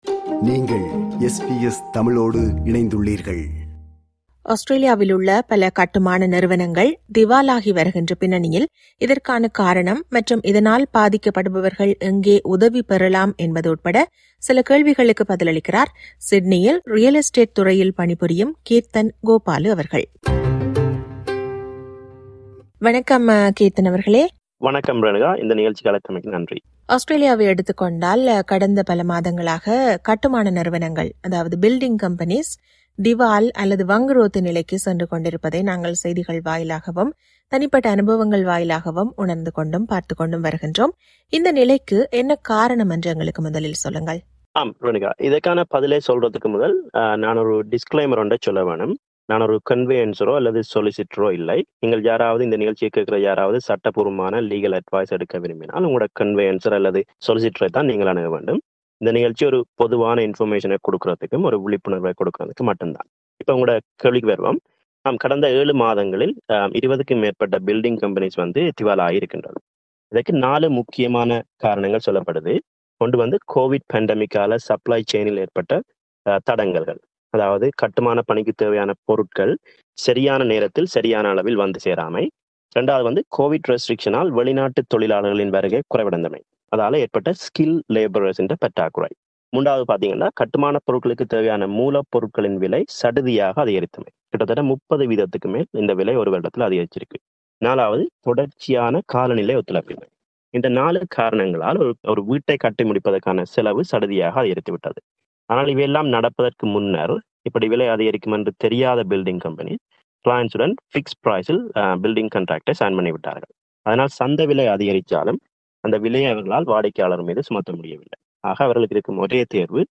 அவரோடு உரையாடுகிறார்